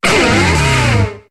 Cri d'Incisache dans Pokémon HOME.